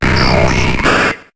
Cri de Spectrum dans Pokémon HOME.